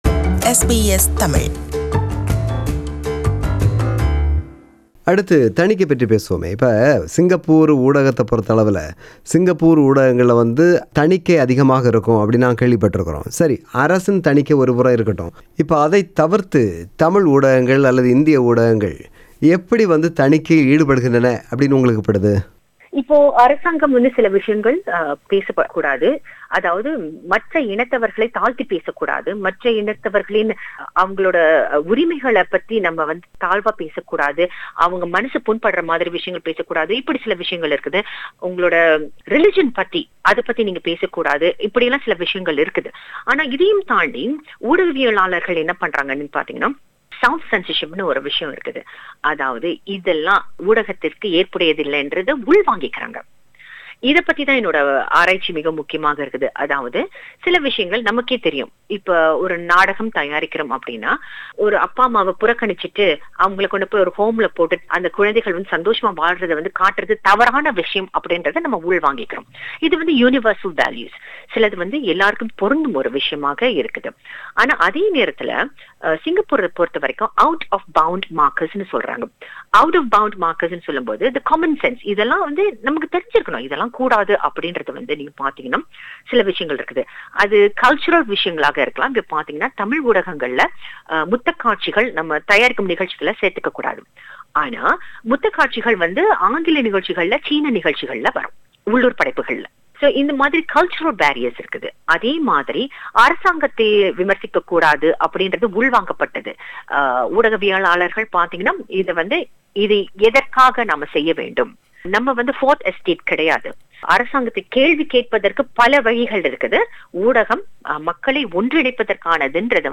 Interview: Part 2.